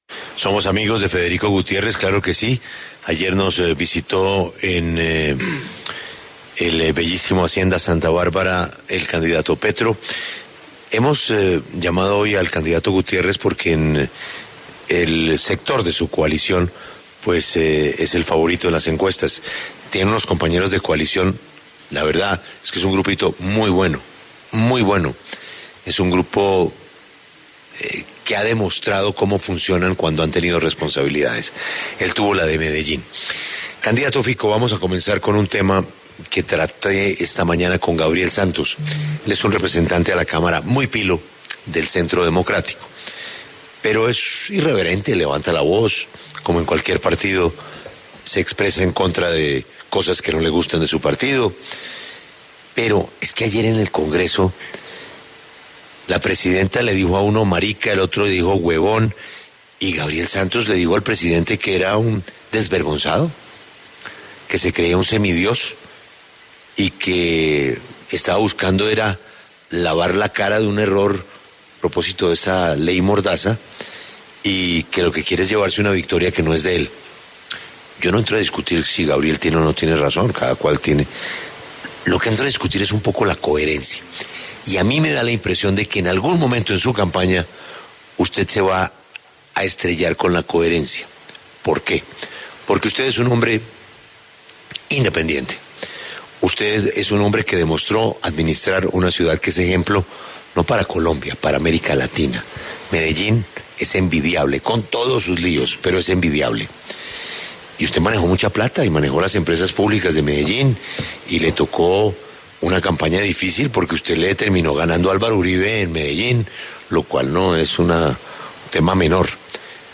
Escuche la entrevista completa a Federico Gutiérrez desde la Sala de Arte y Colecciones en la Torre Atrio Edificio Bancolombia:
Federico Gutiérrez habla con La W desde el Edificio Bancolombia